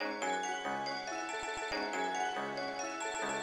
Metro_loopC#m (2).wav